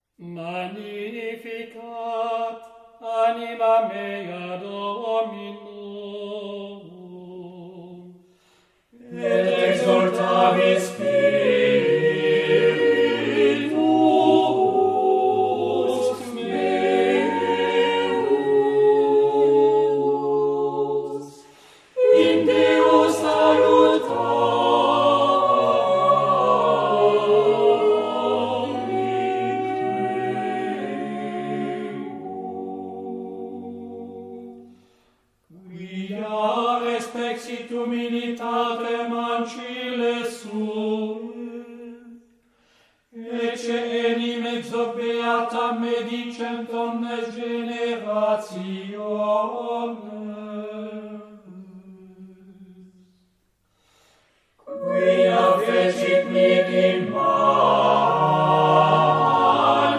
Epoque: 20th century
Genre-Style-Form: Sacred
Type of Choir: SATB  (4 mixed OR unison voices )
Tonality: dorian